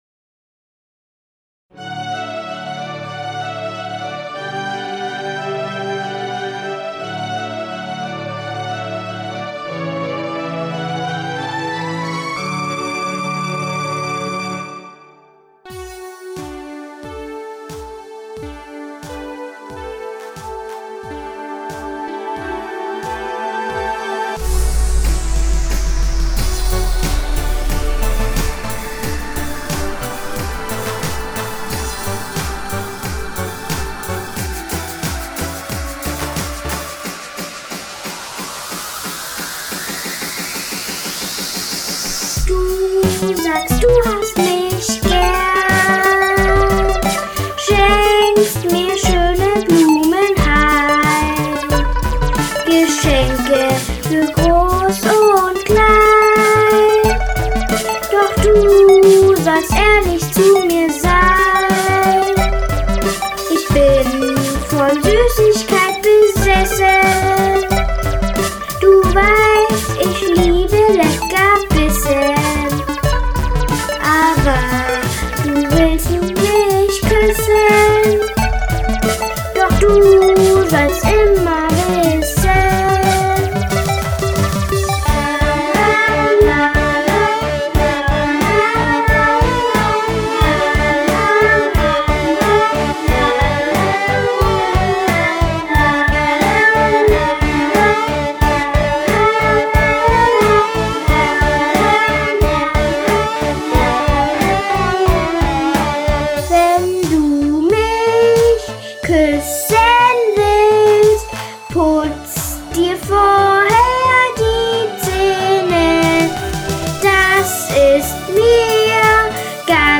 Kids' song